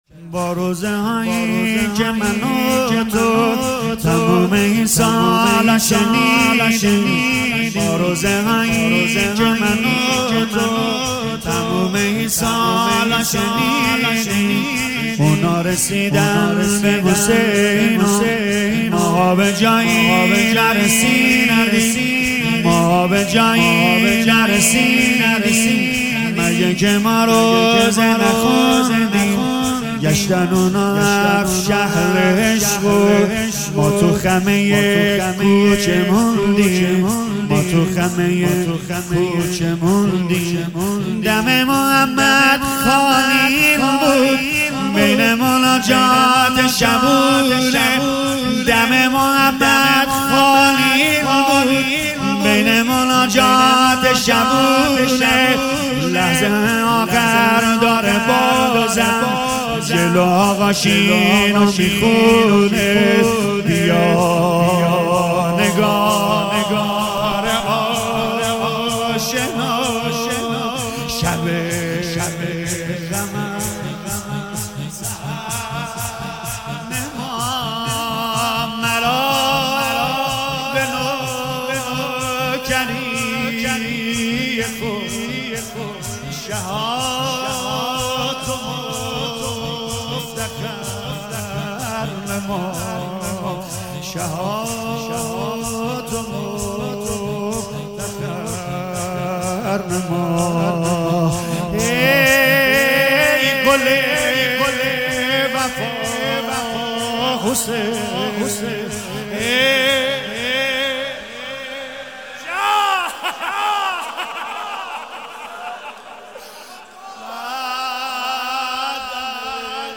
شور حماسی شهدا